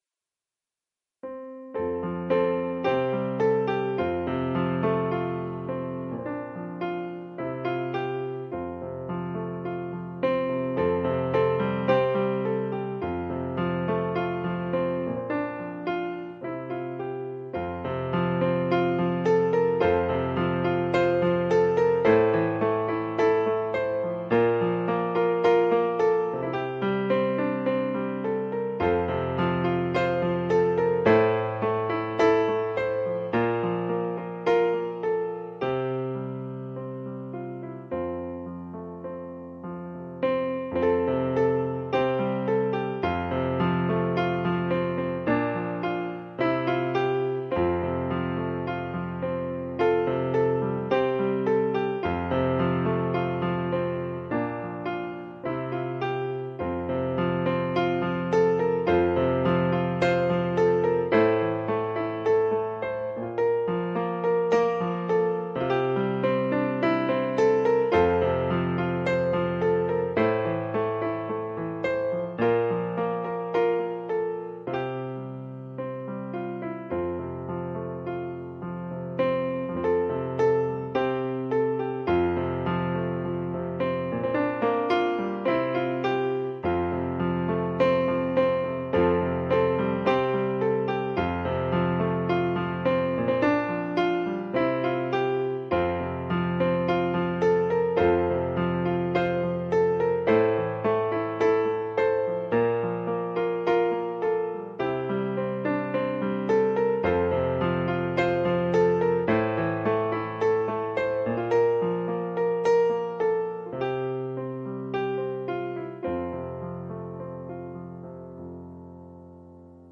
message brought on May 17, 2020